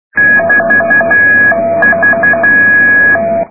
Les fichiers à télécharger sont compressés au format MP3 à 1ko/sec, ce qui explique la très médiocre qualité du son.
bande 2 m, balise HB9HB, FSK, les traits-points sont plus graves que les espaces